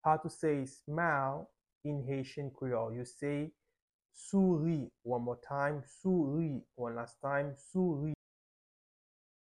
Pronunciation:
19.How-to-say-Smile-in-Haitian-Creole-–-souri-with-pronunciation.mp3